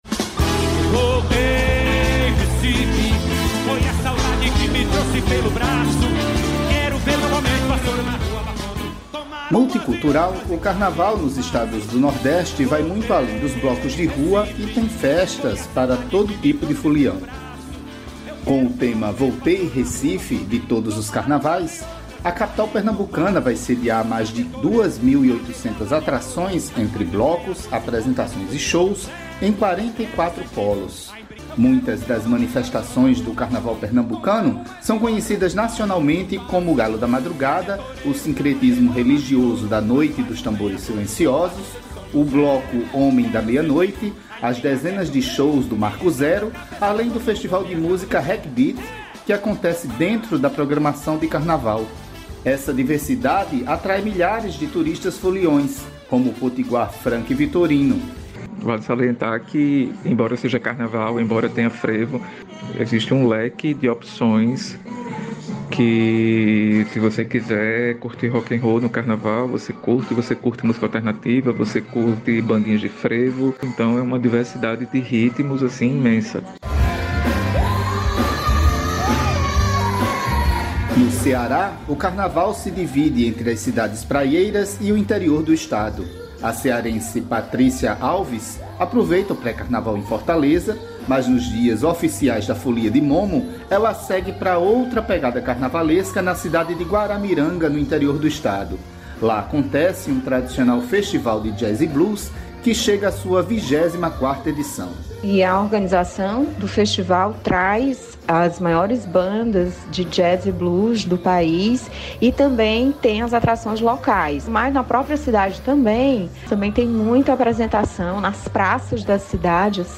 Repórter da Rádio Nacional Carnaval Nordeste multicultural segunda-feira